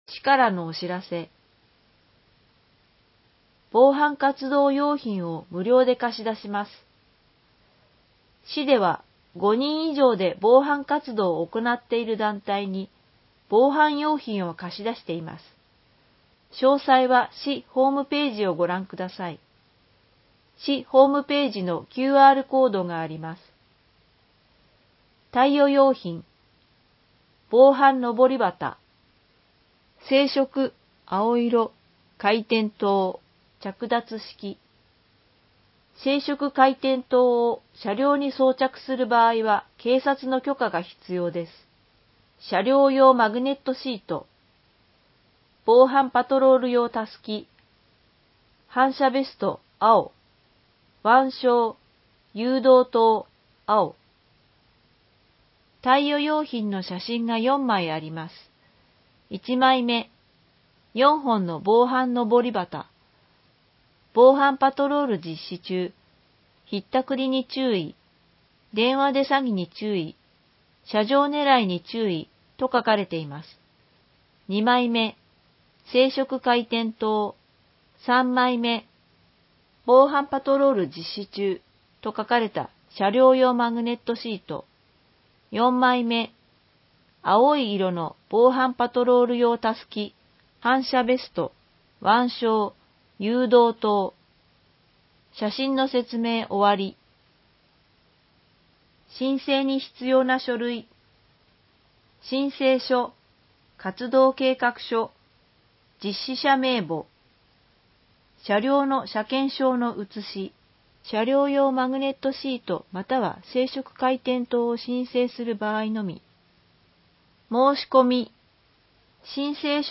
松戸朗読奉仕会のご協力で、広報まつどの音声版を公開しています。